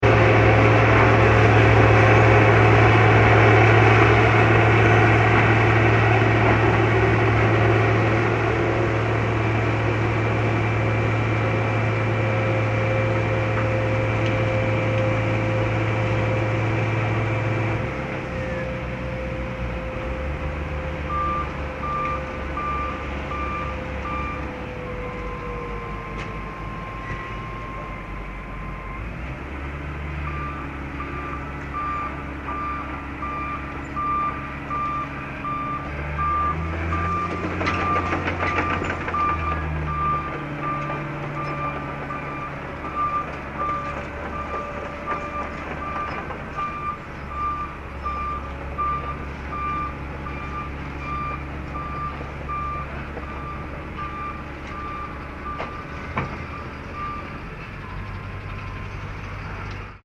Binaural Recording Of Roadageddon Outside My House This Morning